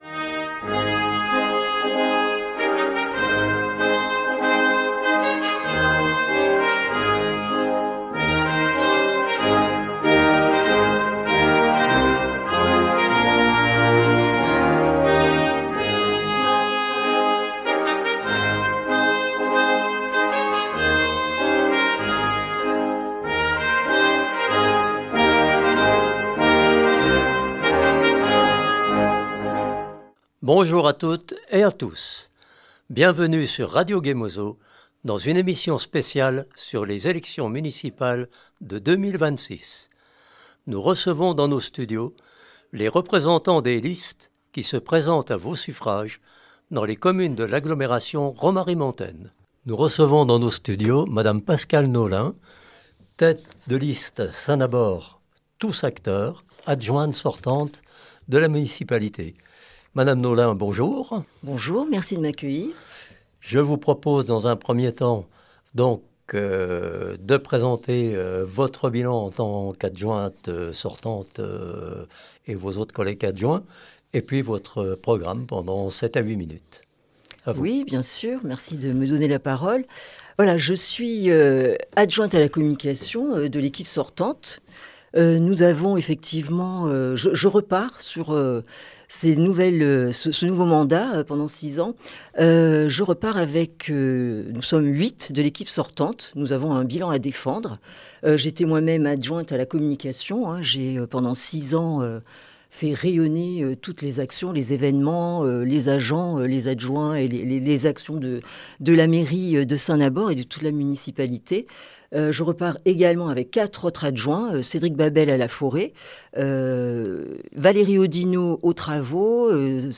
À l’approche des élections municipales, RGM donne la parole aux candidats.